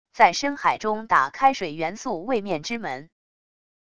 在深海中打开水元素位面之门wav音频